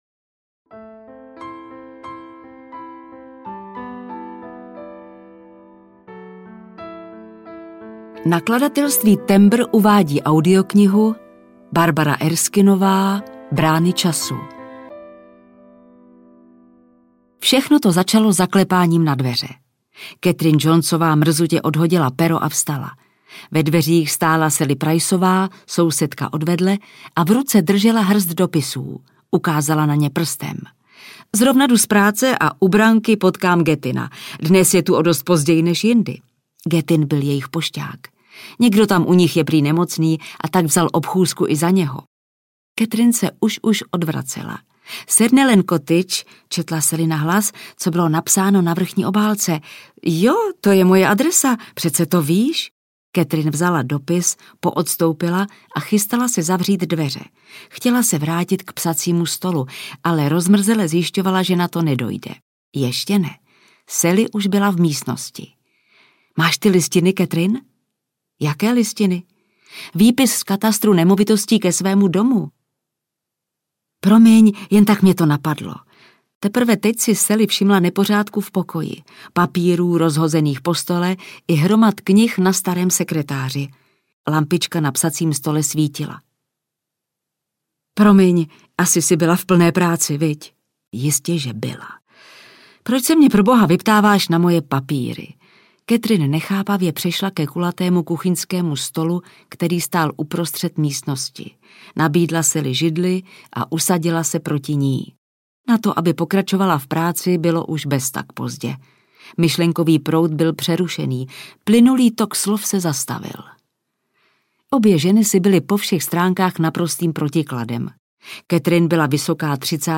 Brány času audiokniha
Ukázka z knihy
Natočeno ve studiu KARPOFON (AudioStory)